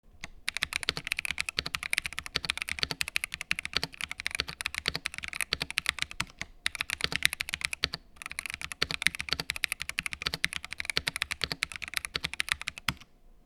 بعد فك مفتاح  Space نري مفتاح Razer Orange  ومن خلال التجربة المفتاح جيد جدا ويتميز بسرعة استجابته و لة صوت مختلف عن المفاتيح الزرقاء من شركة Cherry mx و أيضا المفتاح هنا يوزان بين الاستخدام في الكتابة و الألعاب و ستجد عينة صوتية في موقع Razer و سأضعها لك في الأسفل لنها نفس الصوت دون الحاجة الي تسجيل صوت من خلالي فالصوت بعد هذه التحسينات ممتاز للغاية  ، التدعيمات الخاصة بالمفاتيح الكبيرة ممتازة وسهلة الفك والتركيب وهي سهلة التنظيف وهذا شيء مهم عند التنظيف و ، أيضا تصميم لمبة الإضاءة في الأعلي يجعلها مريحة لحين المستخدم أكثر .
razer-blackwidow-v4-75-orange-typing2.mp3